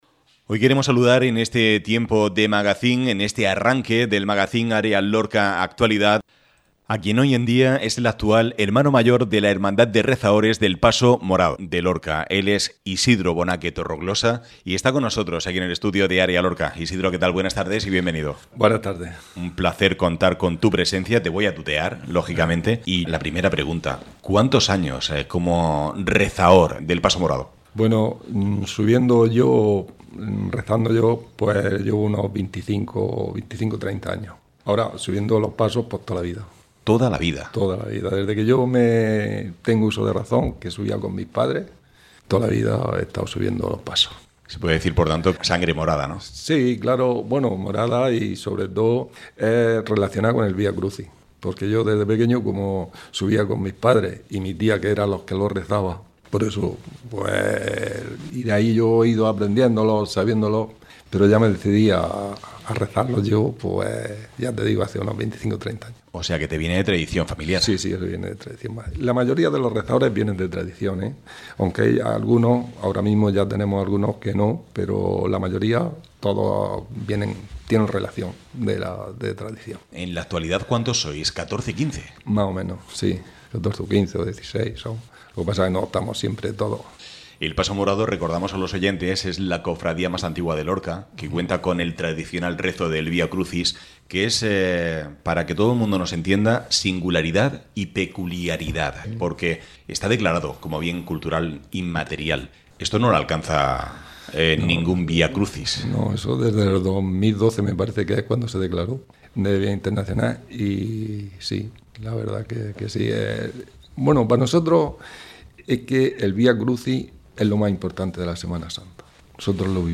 ÁREA LORCA RADIO. Actualidad.